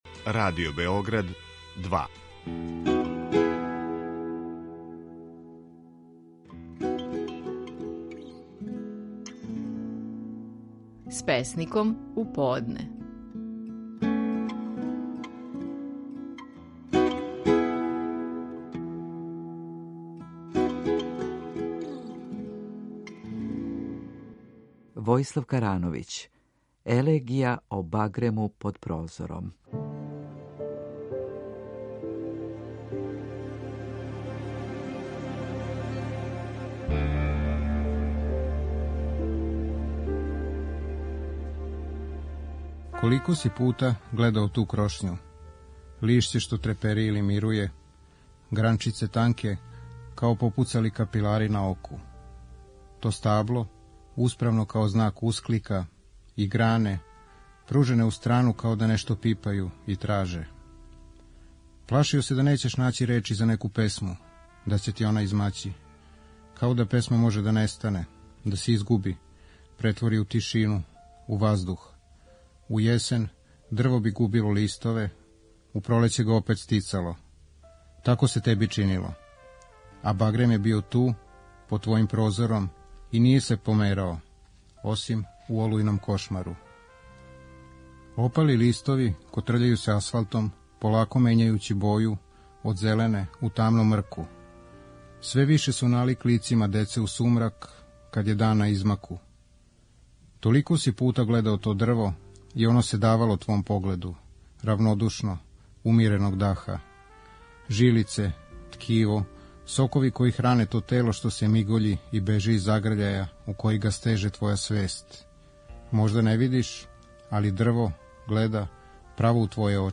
Стихови наших најпознатијих песника, у интерпретацији аутора.
Војислав Карановић рецитује стихове песме „Елегија о багрему под прозором".